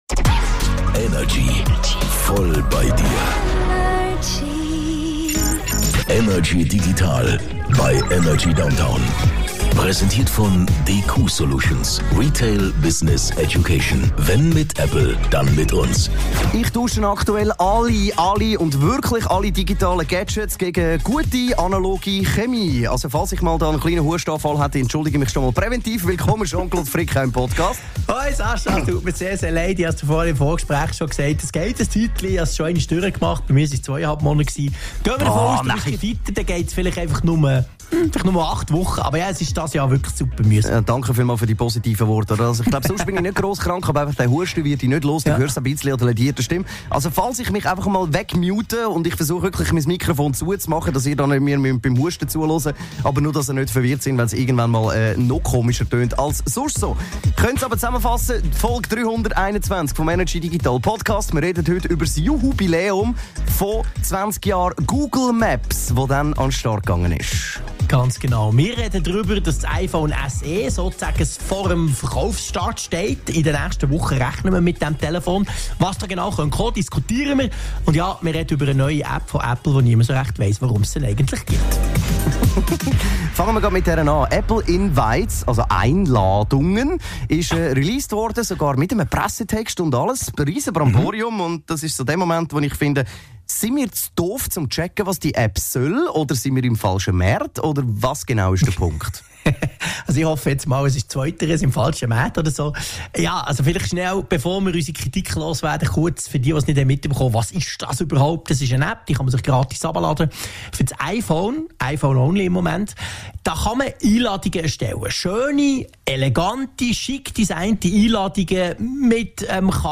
im Energy Studio
aus dem HomeOffice